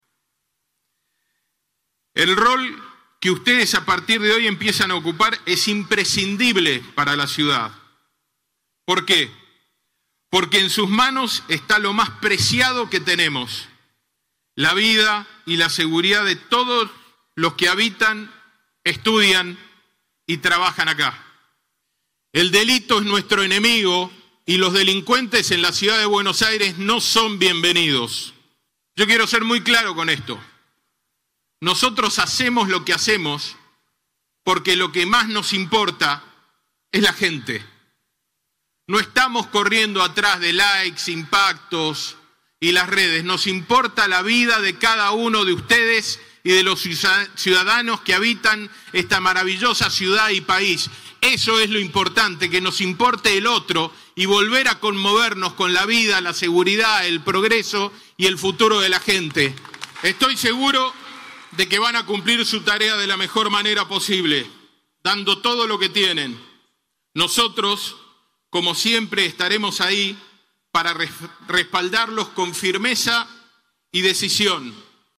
Por primera vez, como lo prometió el Jefe de Gobierno, la jura se realizó fuera del Instituto Superior de Seguridad Pública, y no hubo cupo de ingreso para que los familiares pudieran seguir el emotivo momento desde un lugar más amplio y cómodo, las plateas del Estadio Mary Teran de Weiss.